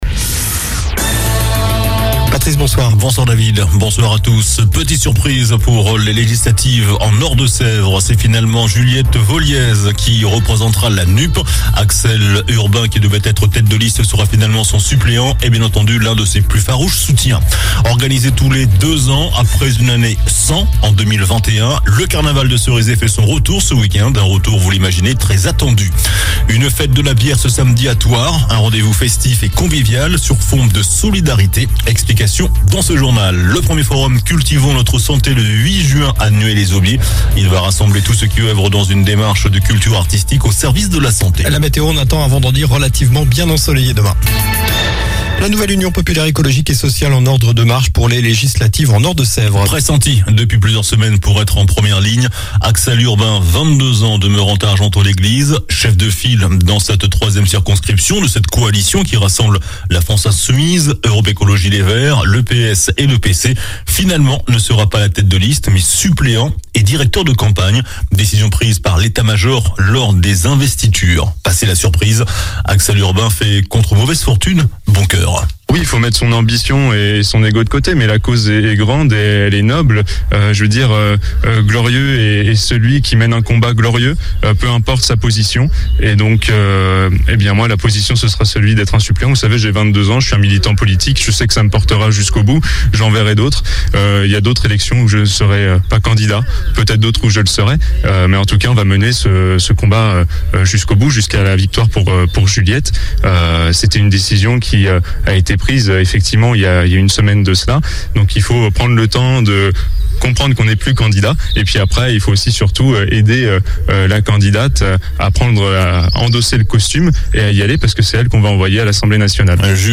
JOURNAL DU JEUDI 12 MAI ( SOIR )